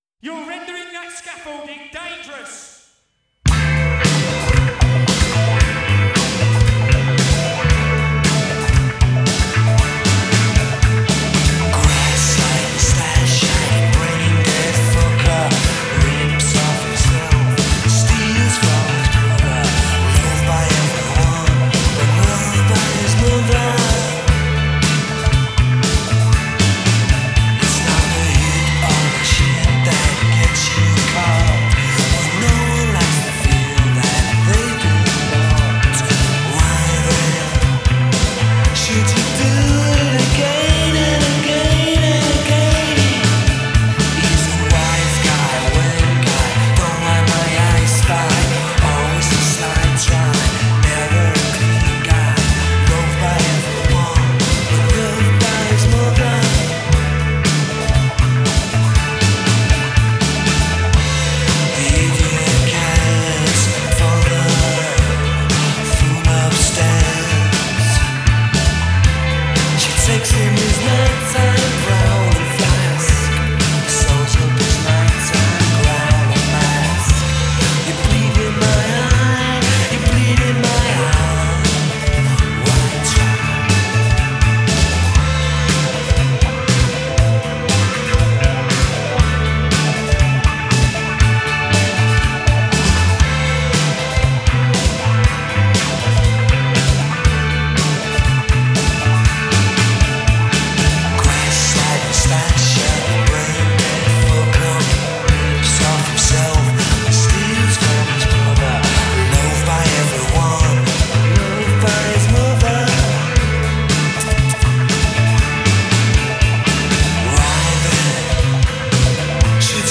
There’s little in the way of finesse about it.